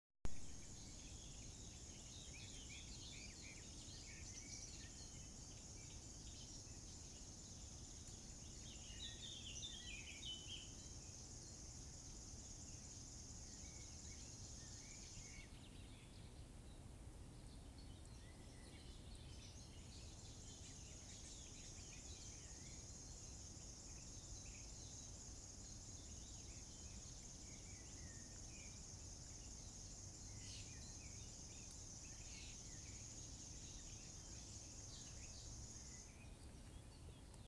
Grasshopper Warbler, Locustella naevia
StatusSinging male in breeding season